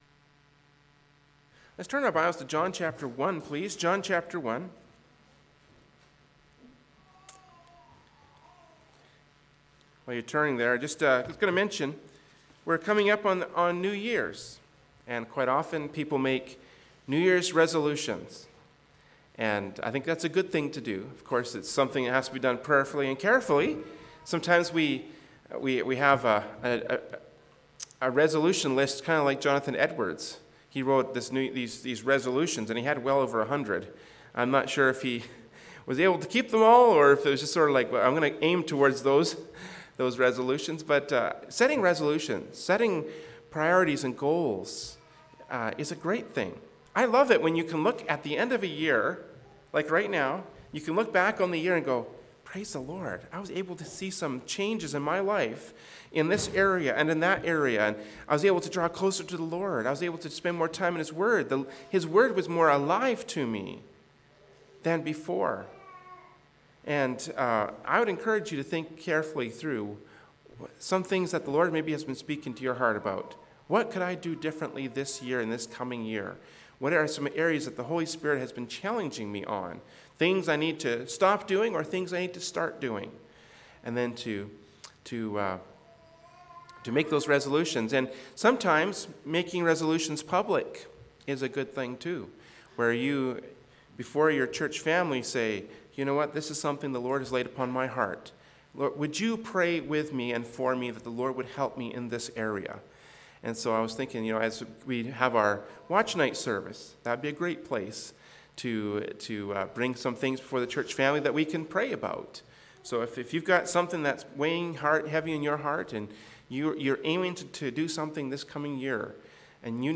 Genre: Preaching.
Passage: John 1:1-12 Service Type: Sunday Morning Worship Service “They Knew Him Not” from Sunday Morning Worship Service by Berean Baptist Church.